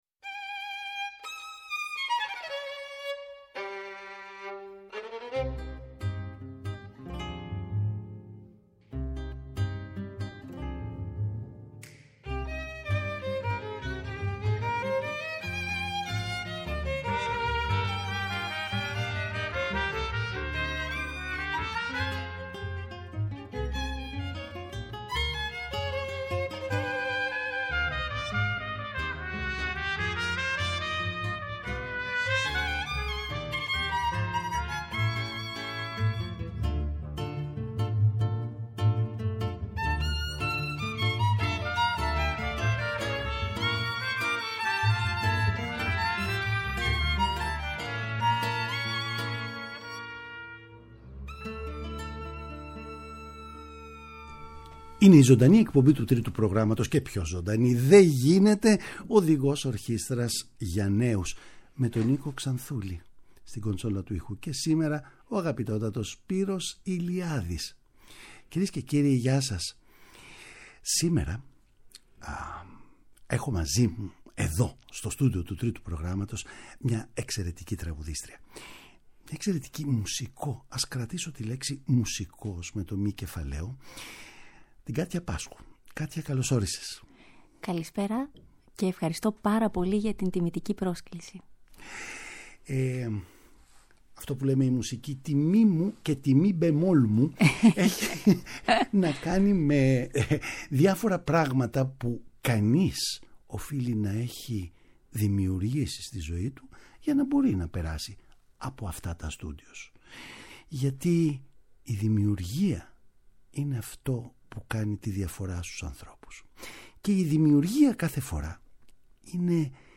Ας την υποδεχθούμε στην εκπομπή μας και ας την απολαύσουμε τόσο δια ζώσης όσο και ηχογραφημένη.
Παραγωγή-Παρουσίαση: Νίκος Ξανθούλης